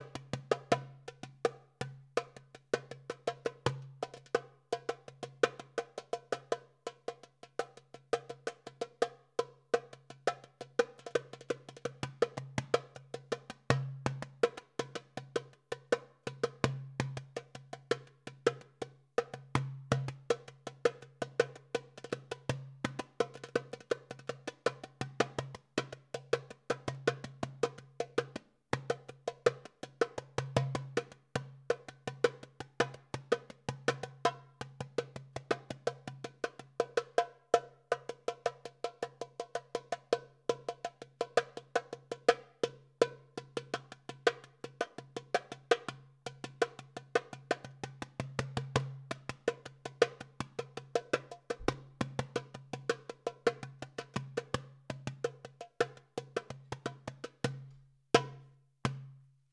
Micing a Dumbec or similar drum.
The first clip is the front mic.
Again you get more mid and highs and less body or lows with the mic in this placement.
dumbek-sm57-front.m4a